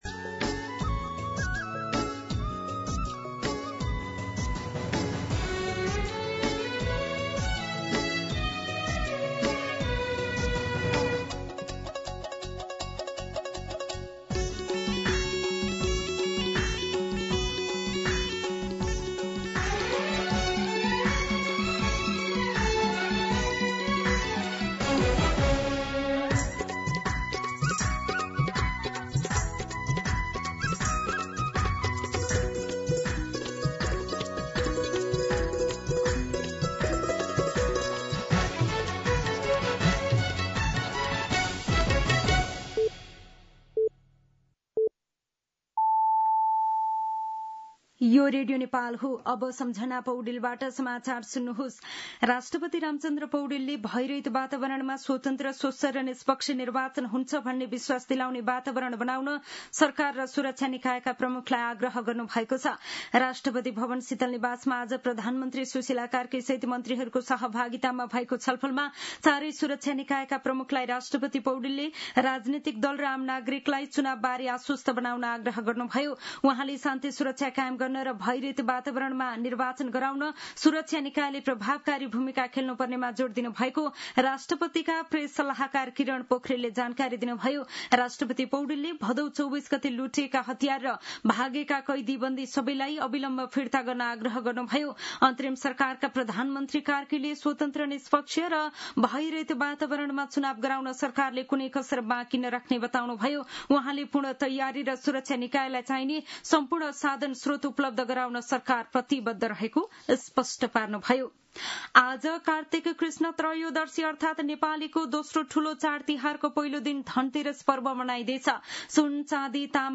An online outlet of Nepal's national radio broadcaster
दिउँसो ४ बजेको नेपाली समाचार : १ कार्तिक , २०८२
4-pm-Nepali-News-8.mp3